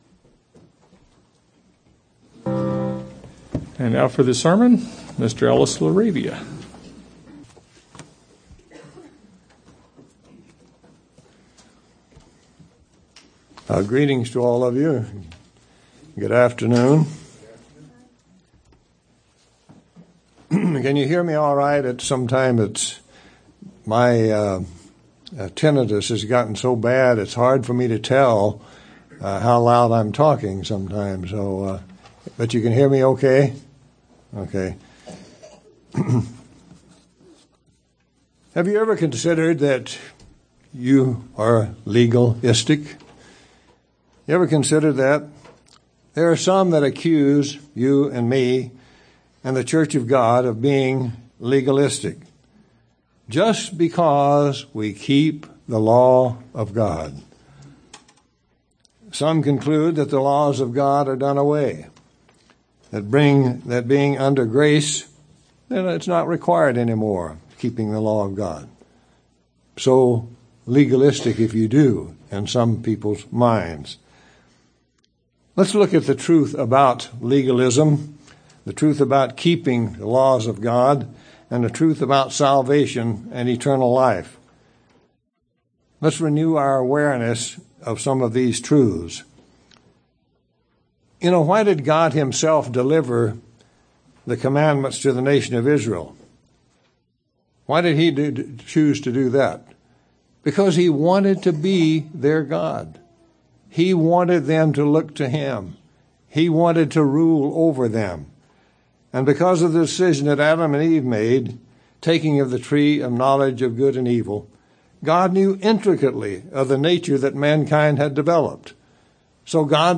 Given in El Paso, TX
UCG Sermon Studying the bible?